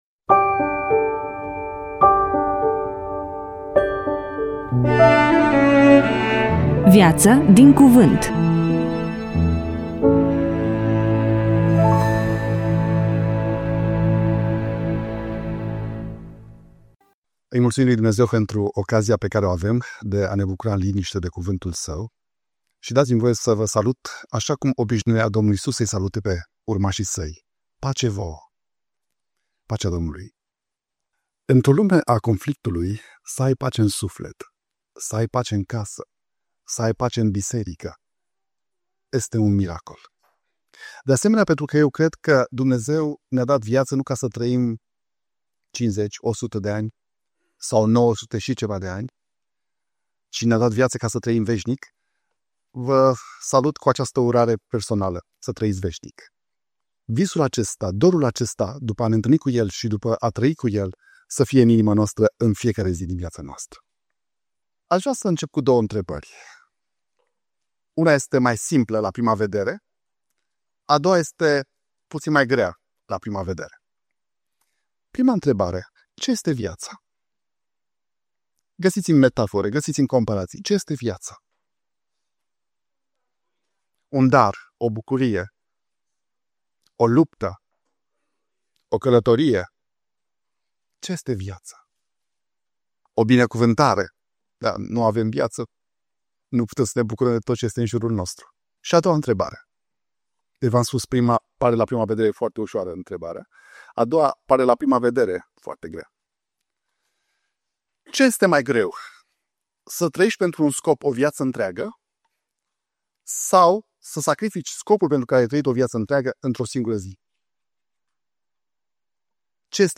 EMISIUNEA: Predică DATA INREGISTRARII: 14.02.2026 VIZUALIZARI: 26